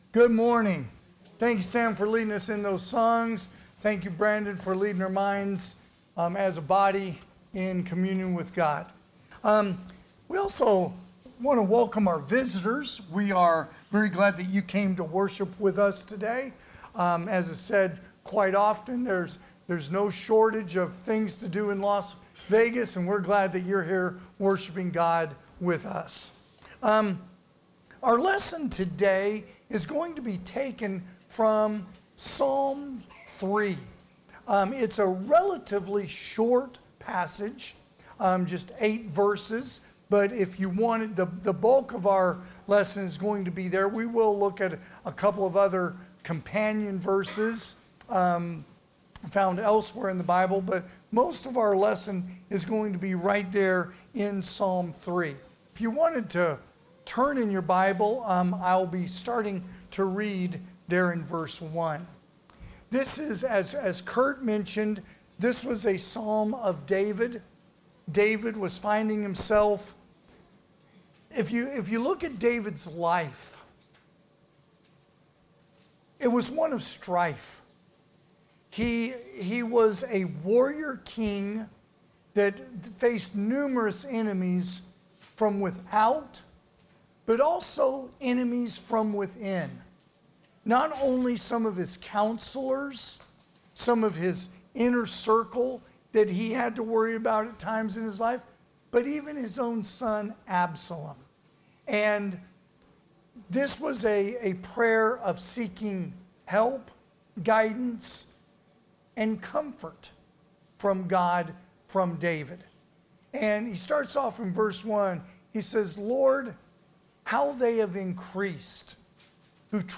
2026 (AM Worship) "Psalms 3"